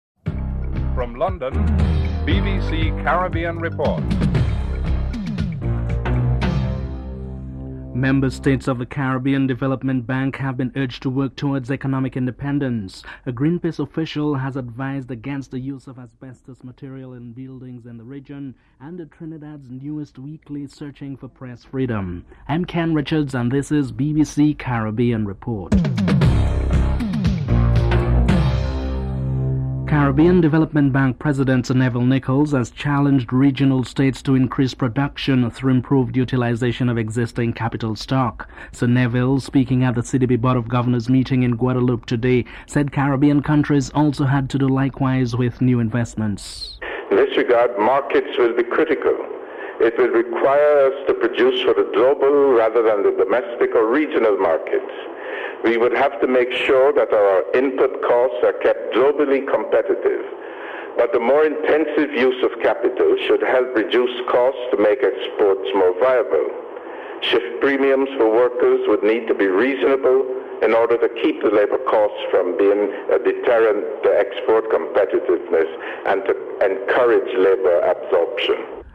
1. Headlines (00:00-00:28)
Prime Minister Keith Mitchell is interviewed (03:02-04:09)